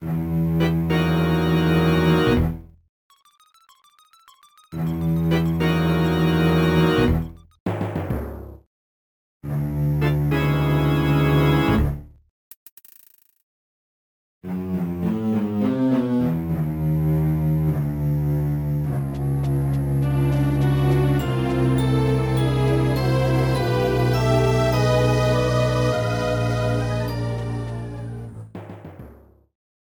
Self-ripped with Nitro Studio 2
Fade out added